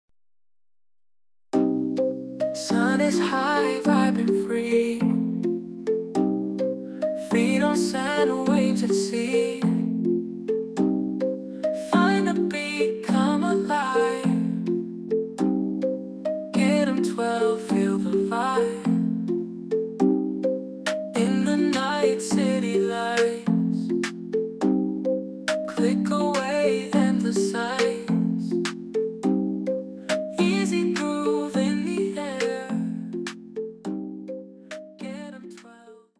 Short version of the song, full version after purchase.
An incredible Afrobeats song, creative and inspiring.